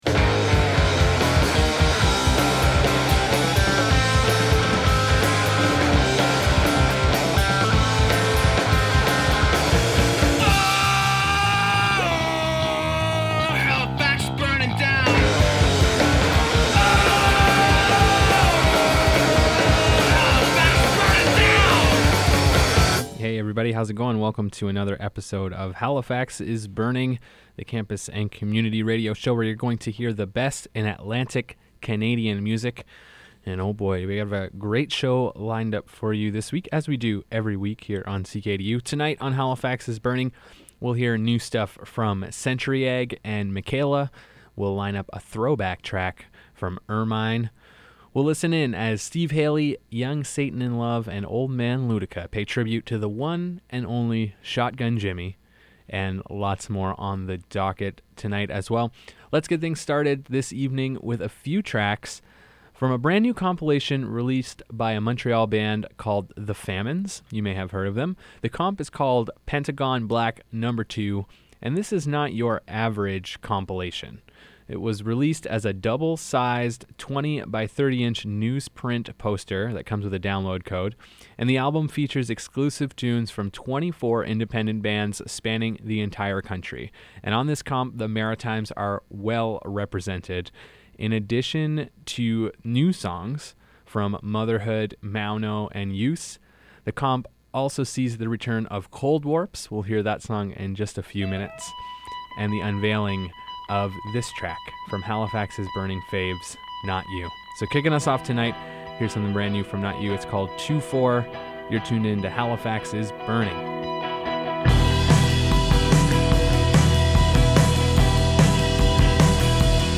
The best independent East Coast music